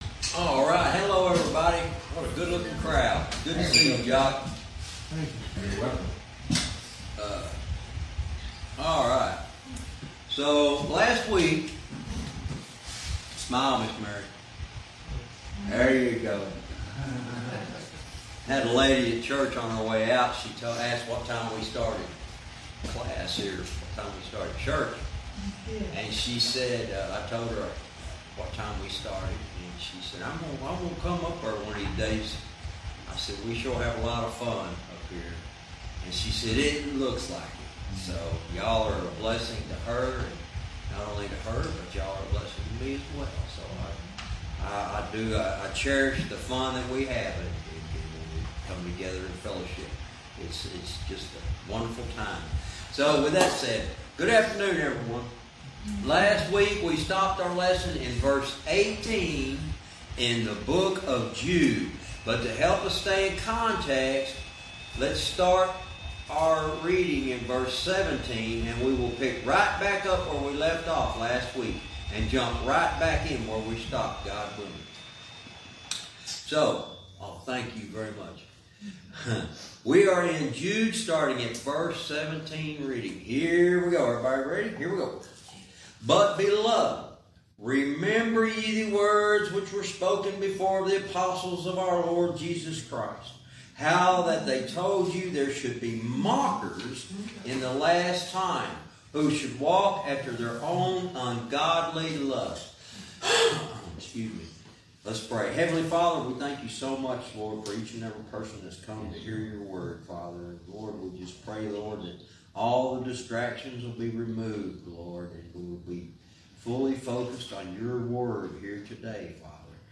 Verse by verse teaching - Jude lesson 81 verse 18